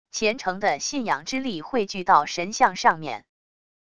虔诚的信仰之力汇聚到神像上面wav音频